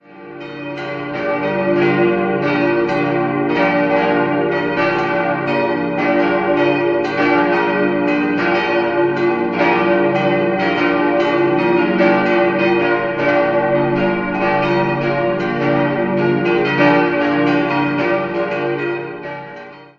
Idealquartett: d'-f'-g'-b' Die Glocken wurden von der Gießerei Engelbert Gebhard in Kempten im Jahr 1947 gegossen.